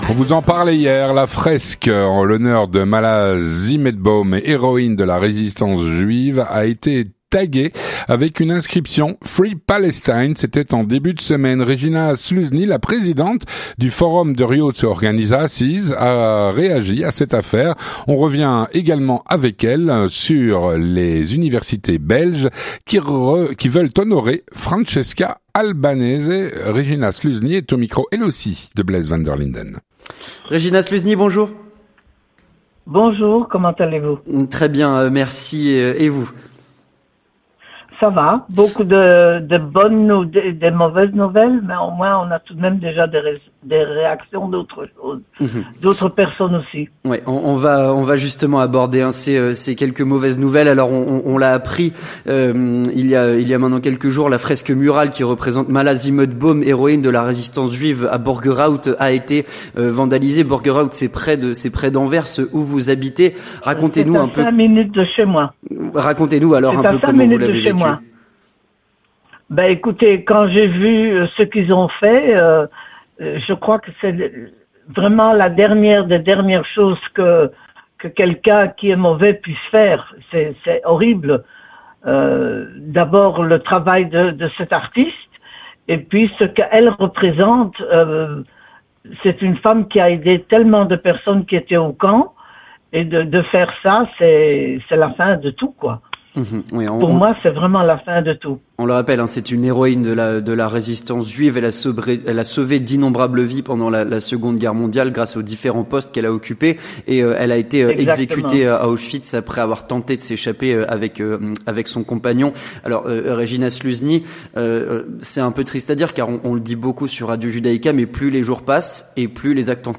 Elle est au micro